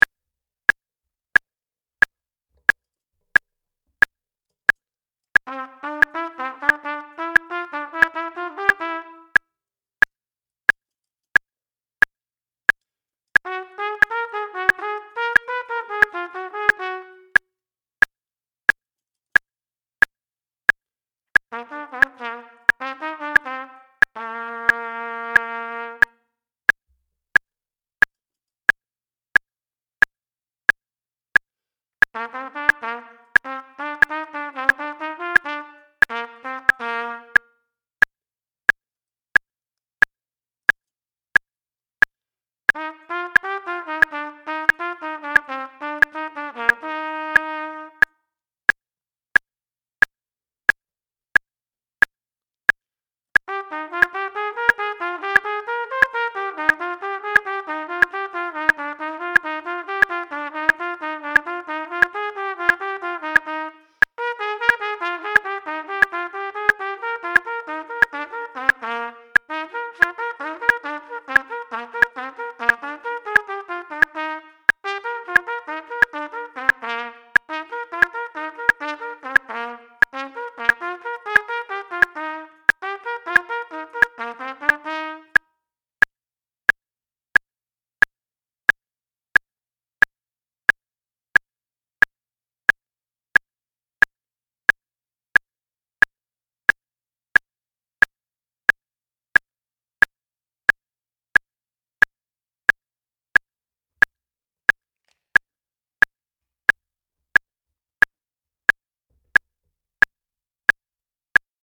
Triple Tonguing Exercises